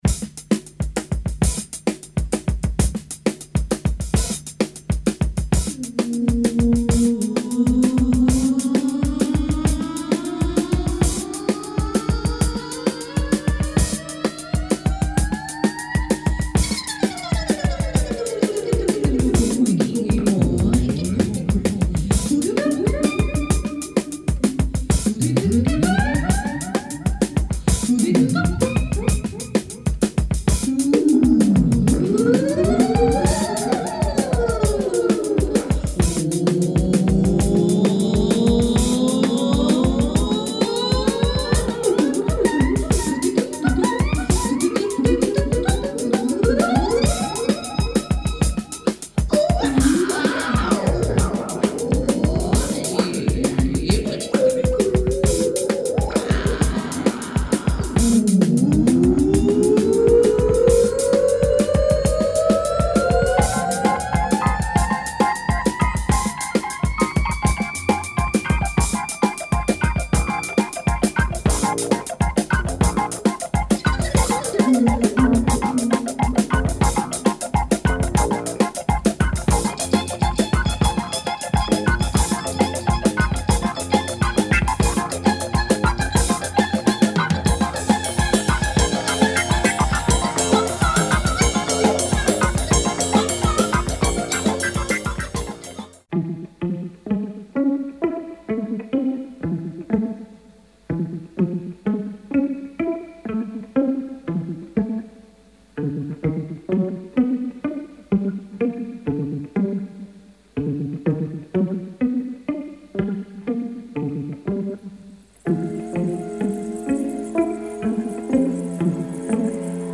jazzman
Some Madlib style samples here